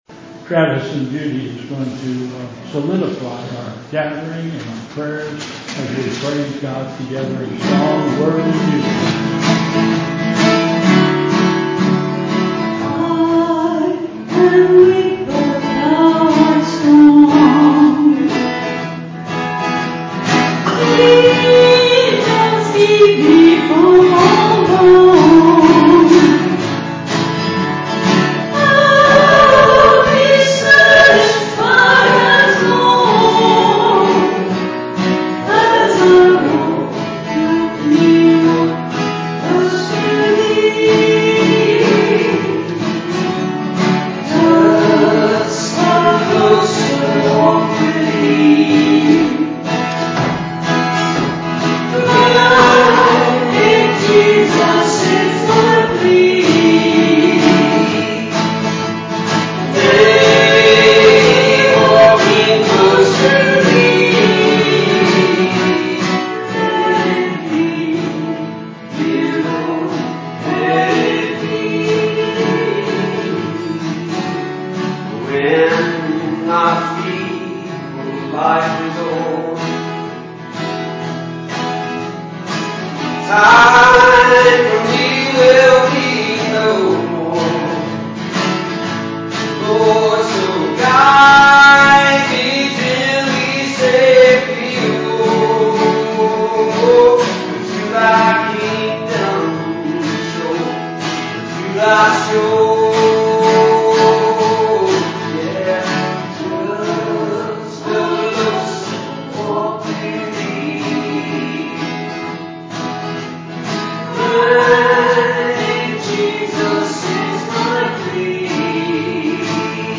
Bethel Church Service
Special Music